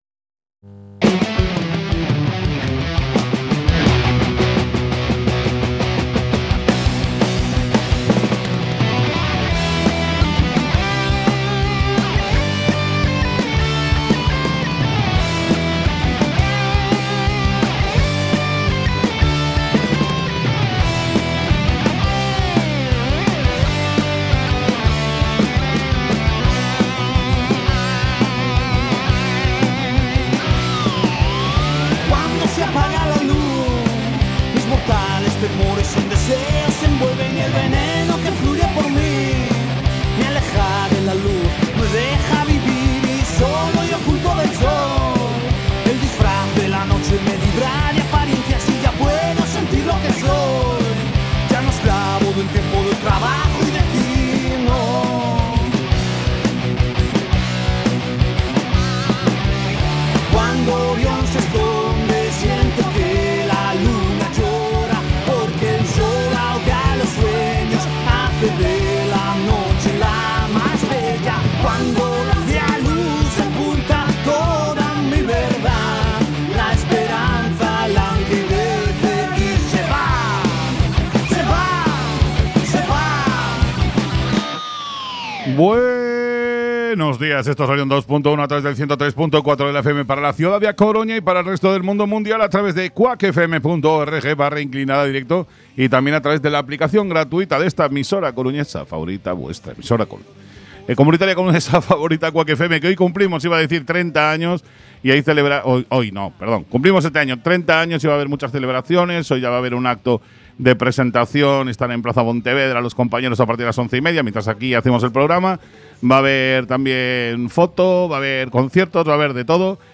Programa de Rock y heavy en todas sus vertientes con un amplio apartado de agenda, de conciertos y eventos, en la ciudad y Galicia. Entrevistas, principalmente a bandas gallegas, y repleto de novedades discográficas.